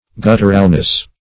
\Gut"tur*al*ness\